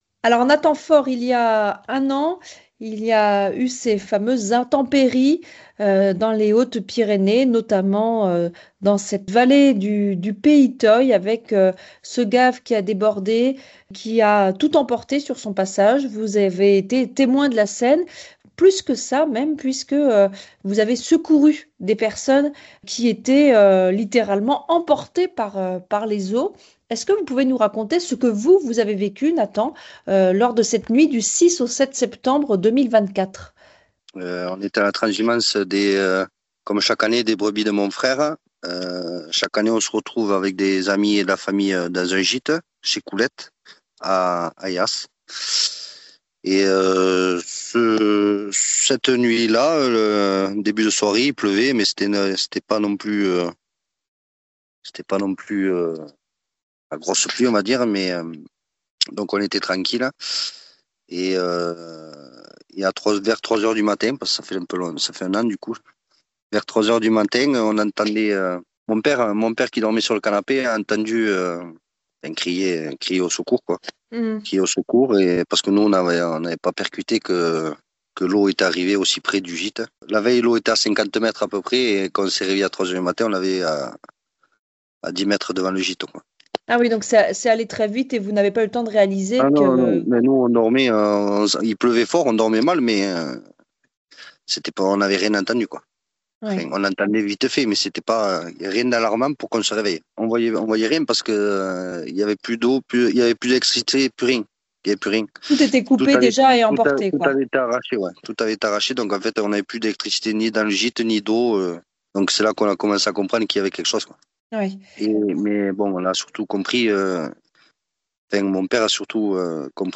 Interview et reportage du 09 sept.